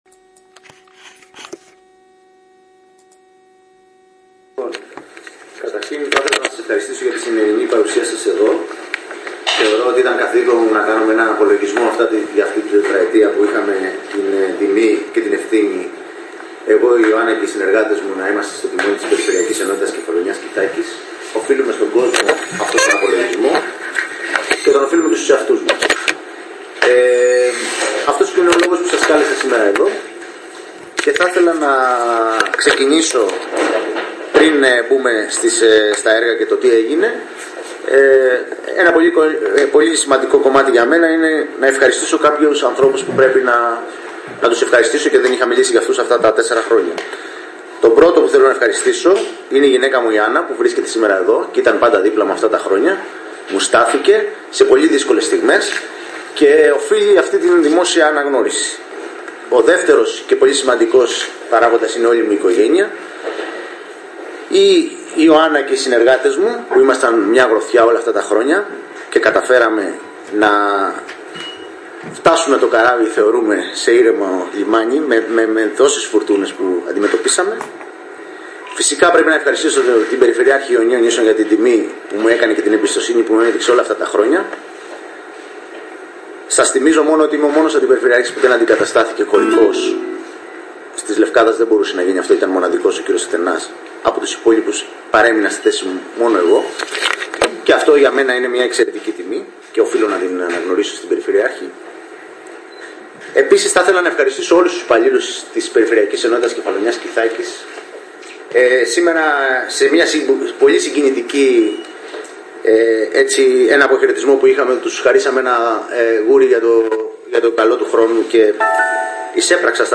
Αποχαιρετιστήρια συνέντευξη του αντιπεριφερειάρχη κ. Σταύρου Τραυλού